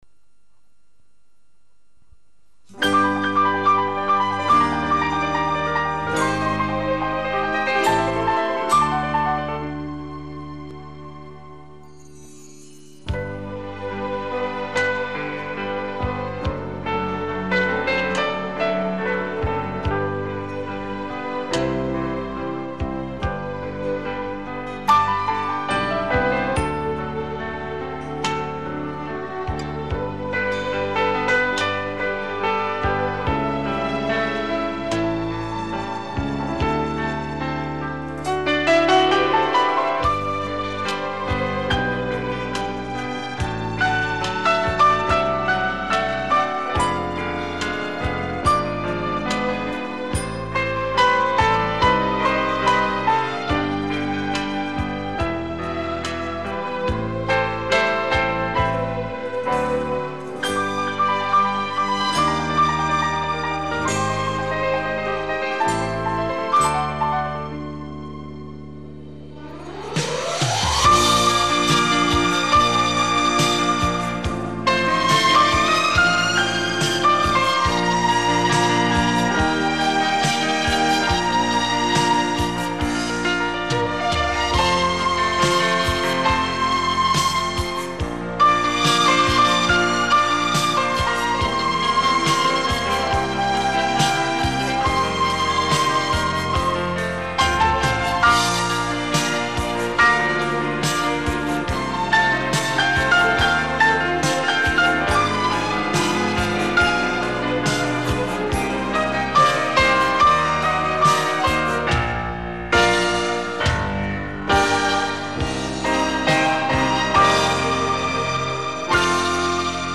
竖笛与歌